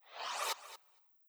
SwooshSlide5.wav